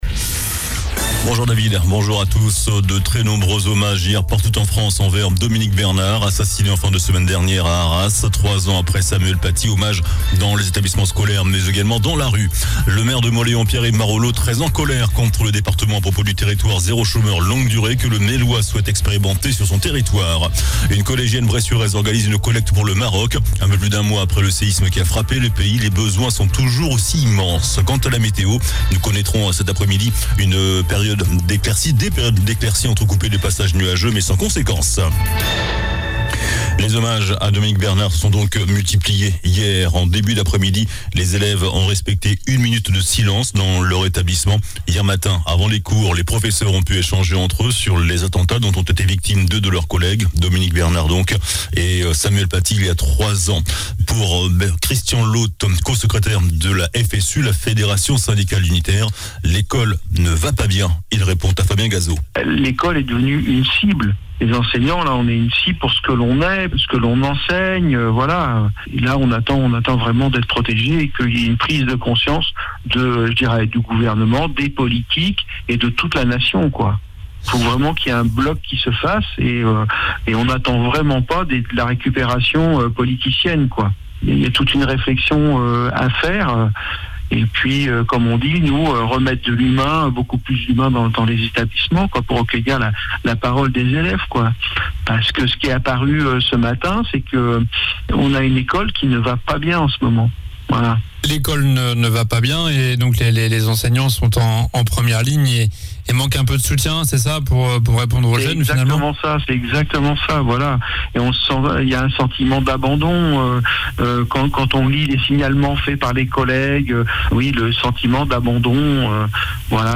JOURNAL DU MARDI 17 OCTOBRE ( MIDI )